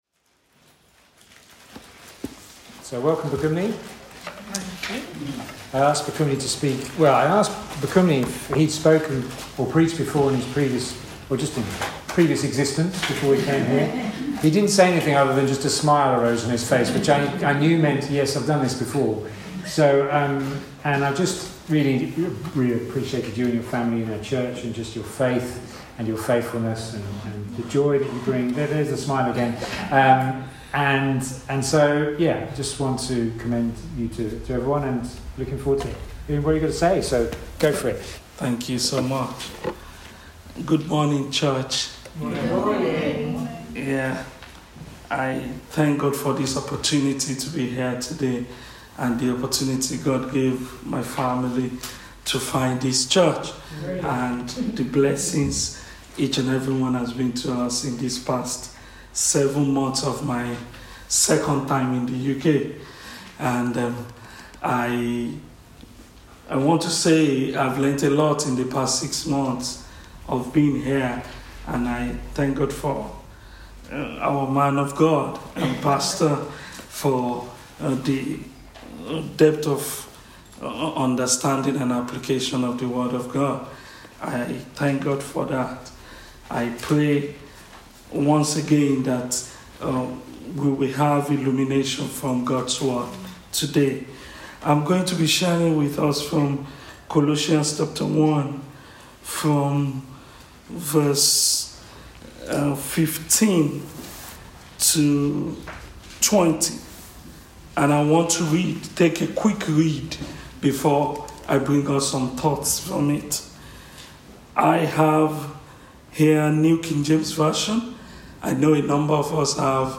Weekly message from The King’s Church.